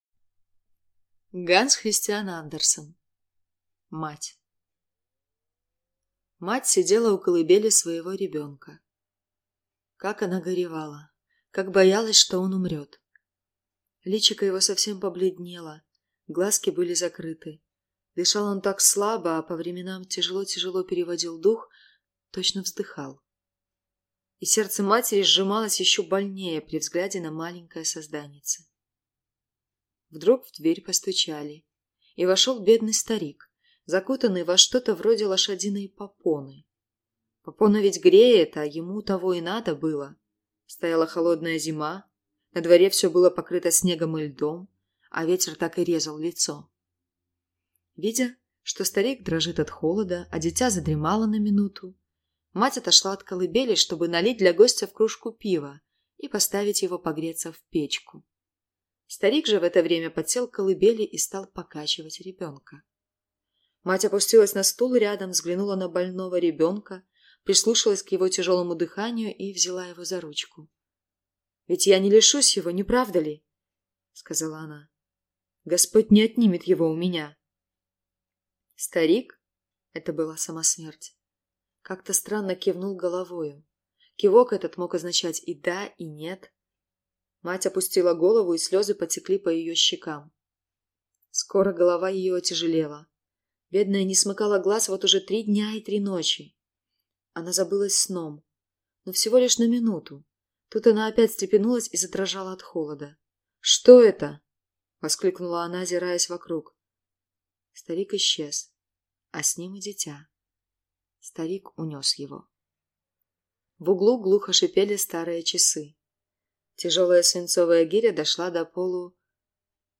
Аудиокнига Мать | Библиотека аудиокниг